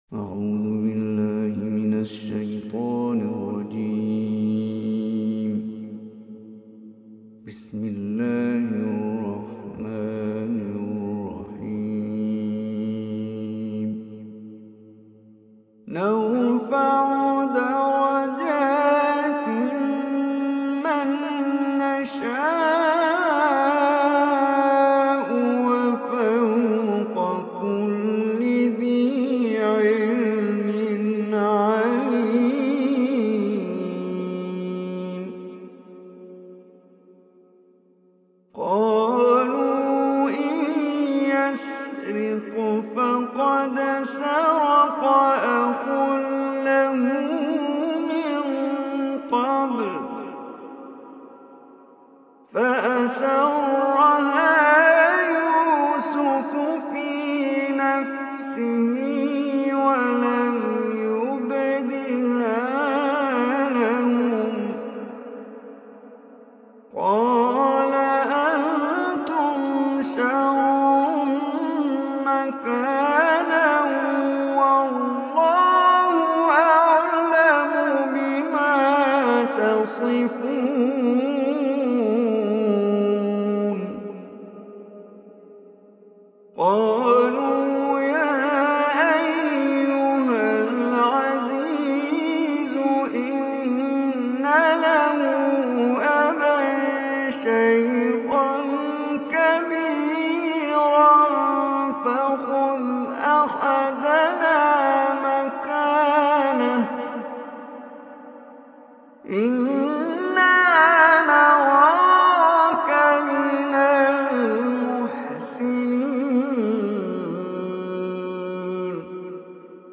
Surah Yusuf Recitation By Omar Hisham al Arabi
Surah Yusuf, is 12 surah of Holy Quran. Listen or play online mp3 recitation of Surah Yusuf in the voice of Omar Hisham Al Arabi.